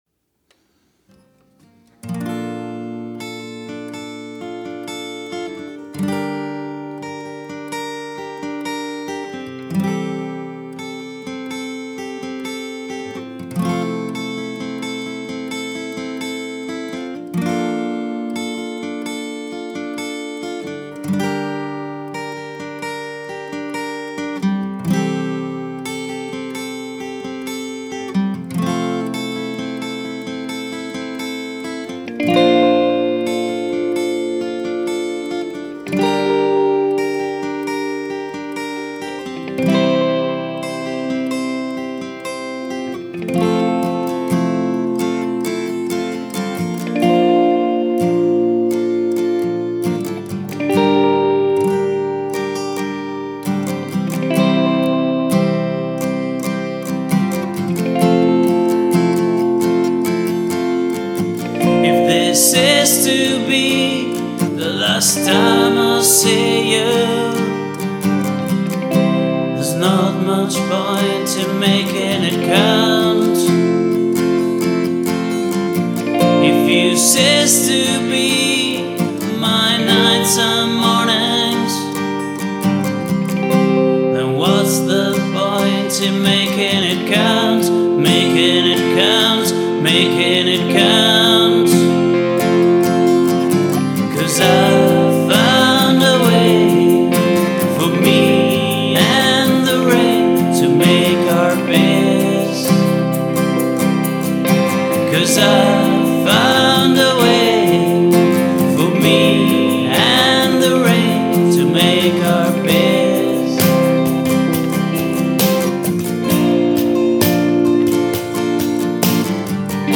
vocals, guitar, drums, percussion